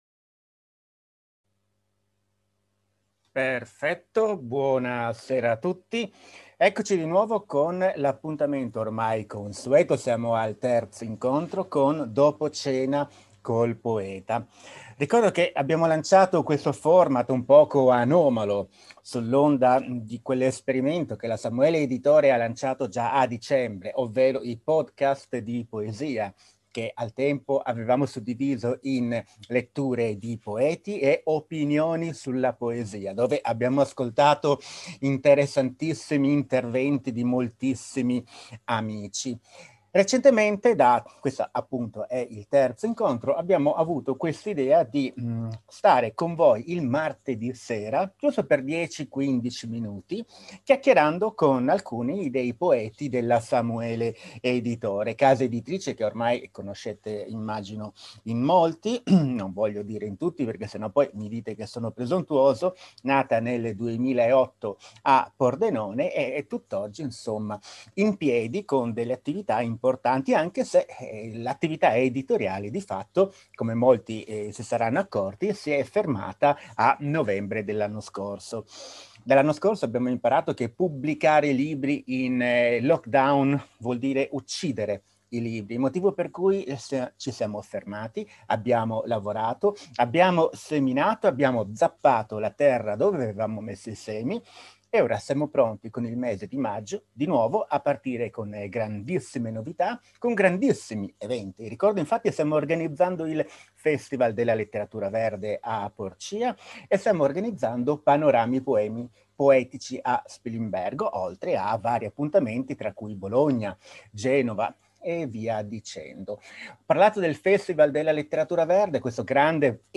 Piccole dirette in solo streaming audio coi poeti della Samuele Editore